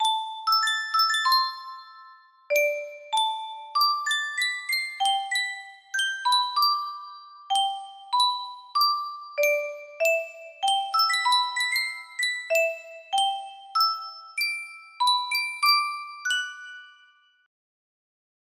Yunsheng Custom Tune Music Box - TFTEISYF music box melody
Full range 60